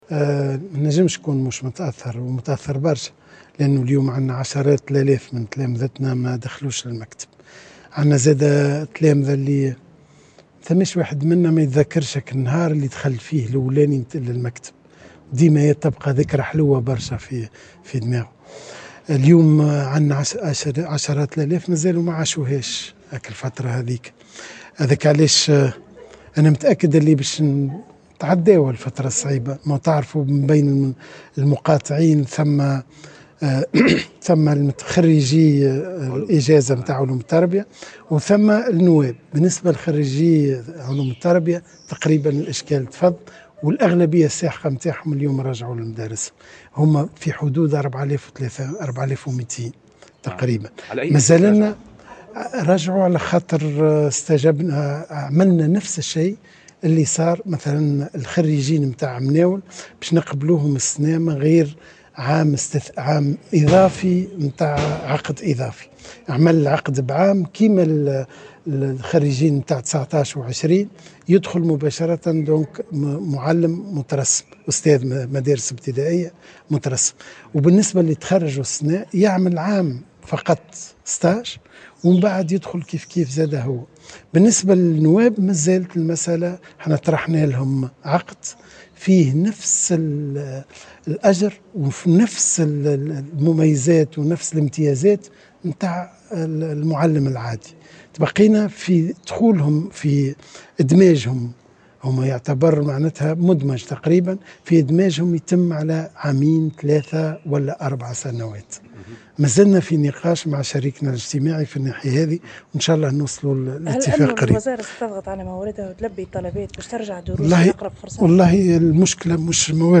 عبّر وزير التربية فتحي السلاوتي، على هامش موكب تسلم المركز الوطني للتكوين وتطوير الكفاءات، شهادة الجودة حسب المواصفات الدولية، اليوم الجمعة، عن ثقته في تجاوز الأزمة التي يمر بها القطاع، في ظل تواصل مقاطعة الأساتذة النواب للعودة المدرسية، خاصة بعد النجاح في تسوية وضعية خريجي علوم التربية، في انتظار التوصل إلى اتفاق يخص المدرسين النواب.